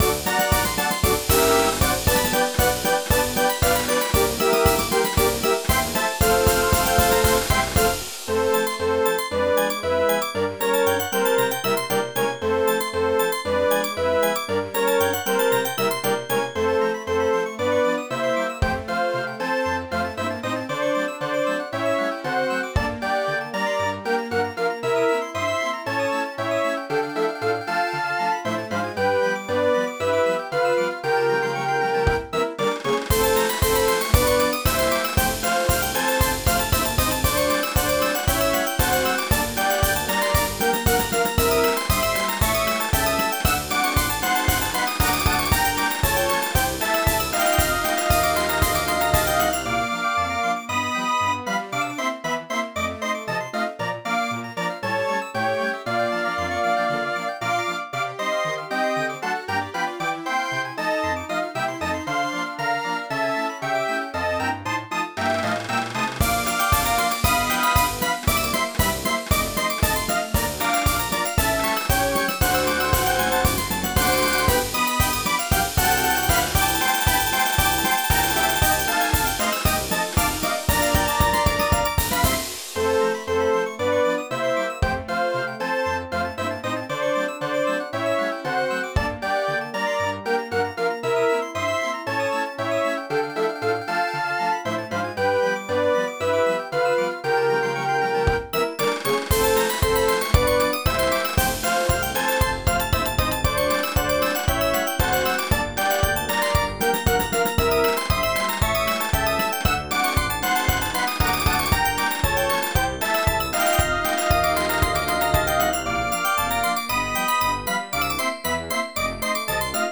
Band organ
When I was tinkering around with MIDI music files (see my MIDI page) I found a delightful little march called 'Painting the town red, white and blue'.
It simulated what was called a Band Organ - we would call it a fairground organ and you can listen to it here.